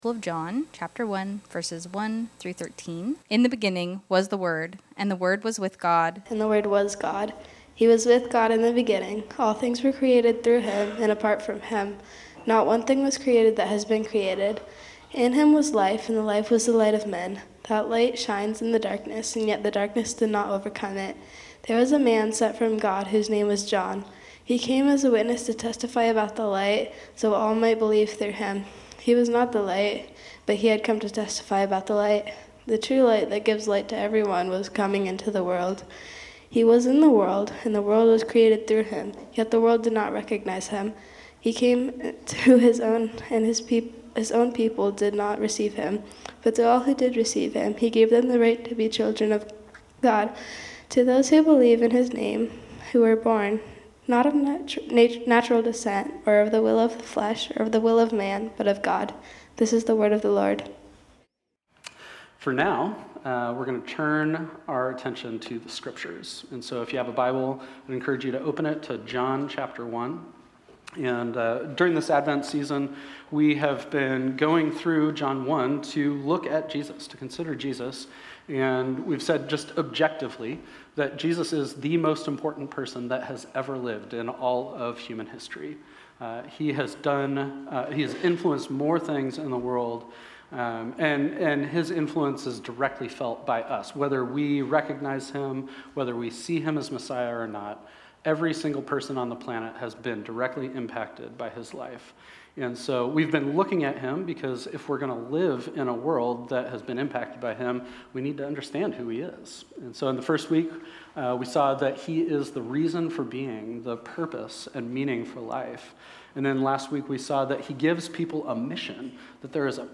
This sermon was originally preached on Sunday, December 21, 2025.